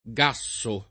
gassare v.; gasso [